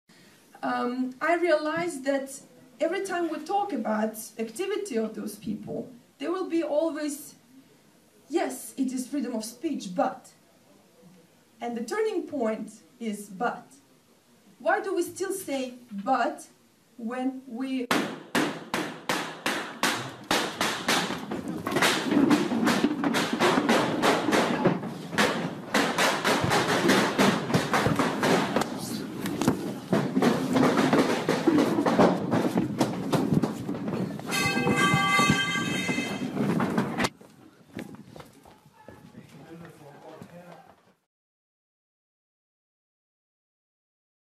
ΤΟ ΗΧΗΤΙΚΟ ΝΤΟΚΟΥΜΕΝΤΟ ΤΗΣ ΣΥΖΗΤΗΣΗΣ ΣΤΟ ΚΑΦΕ ΤΗΣ ΚΟΠΕΝΧΑΓΗΣ ΟΤΑΝ ΑΚΟΥΓΟΝΤΑΙ ΟΙ ΠΥΡΟΒΟΛΙΣΜΟΙ
Στο διαδίκτυο διέρρευσε τo ηχητικό ντοκουμέντο από την επίθεση στο πολιτιστικό καφενείο της Κοπενχάγης όπου γινόταν συζήτηση για το ζήτημα της ελευθερίας έκφρασης .
Η ανοιχτή συζήτηση περί της ελευθερίας της έκφρασης σίγασε υπό τους ήχους των απανωτών πυροβολισμών.